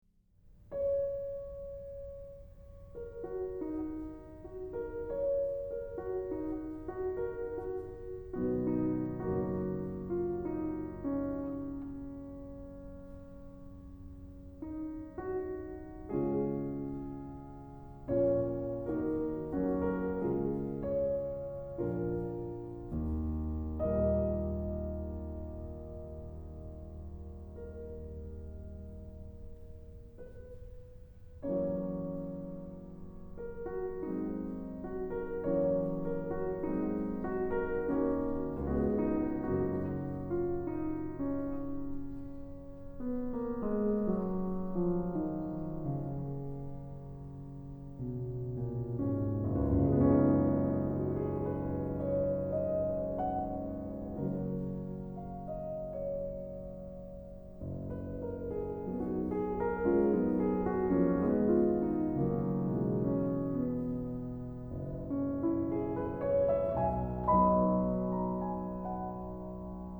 ☆ 精選12位古典作曲大師，19首曲調和緩柔美，旋律甜美動人的鋼琴獨奏作品，舒緩身心靈的最佳音樂。